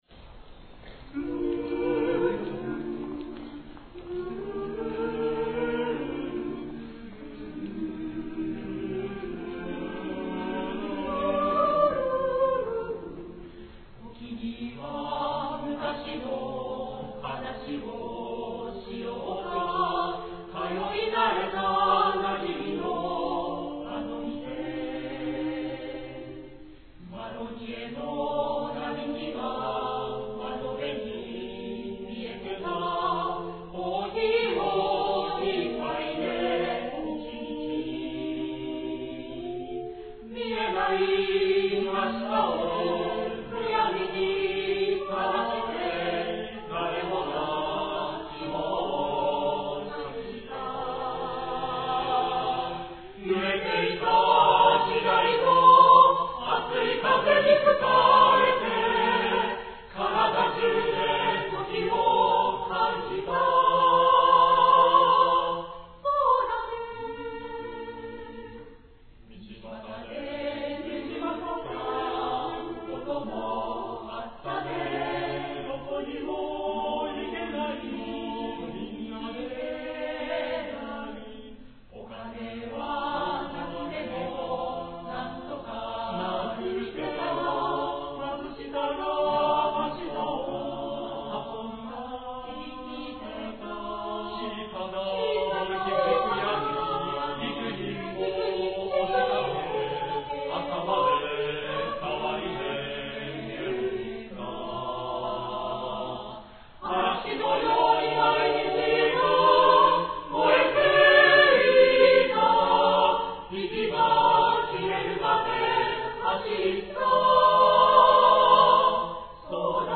第４７回愛知県合唱祭（愛知県勤労会館）に出演しました。